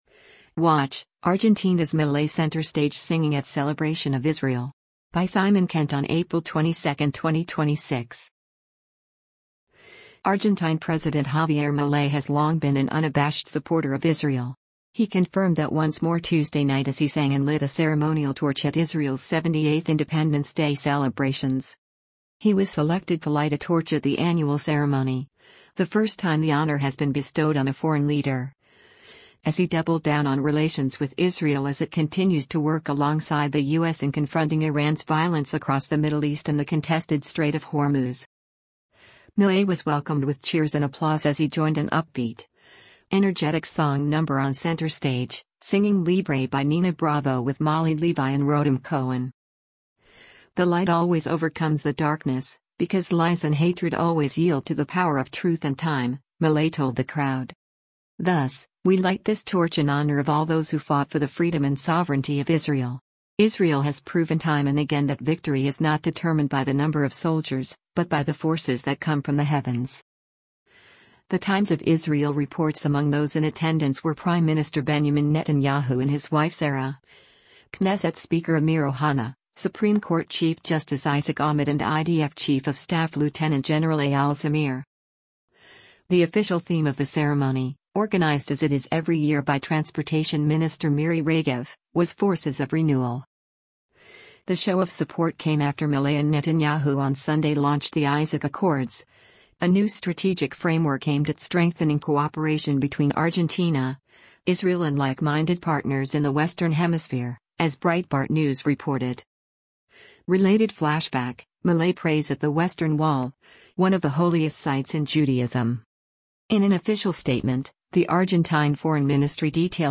He confirmed that once more Tuesday night as he sang and lit a ceremonial torch at Israel’s 78th Independence Day celebrations.
Milei was welcomed with cheers and applause as he joined an upbeat, energetic song number on center stage